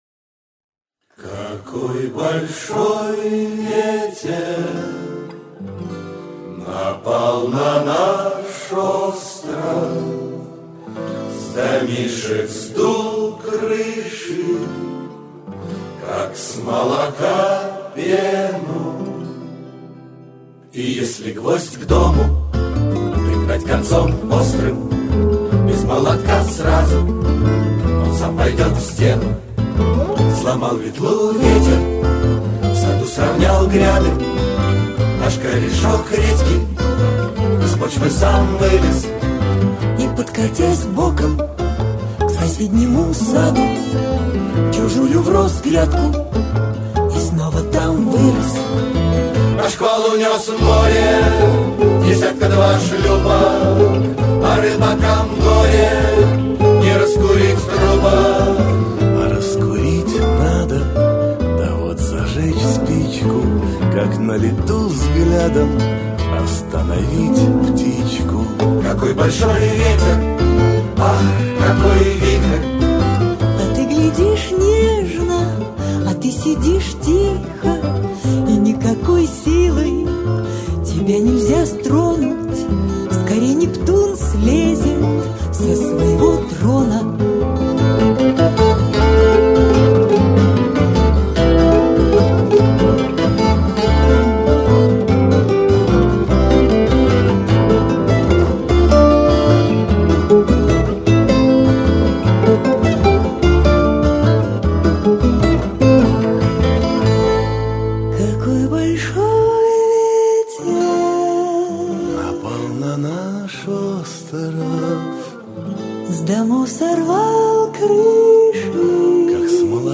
Ансамбль исполнителей авторской песни
Целый хор получился:)